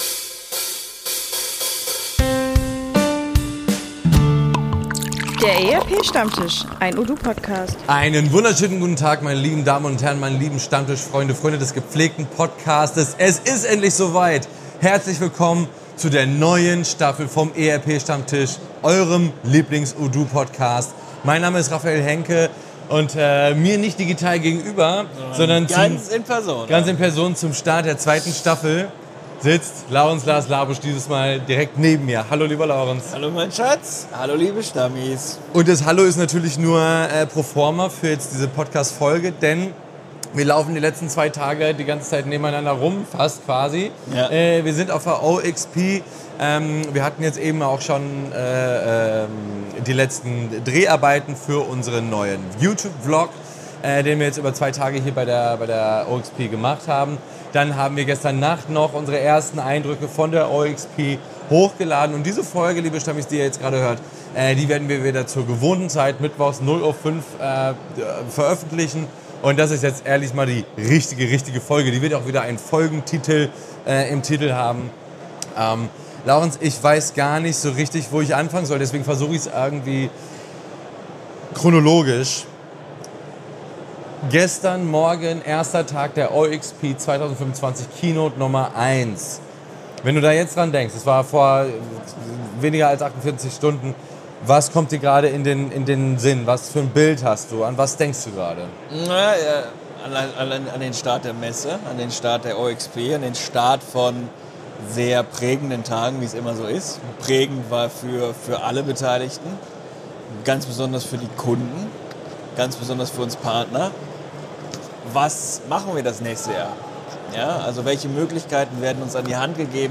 Die beiden berichten direkt vom Geschehen und nehmen euch mit in eine neue Ära der Unternehmenssoftware.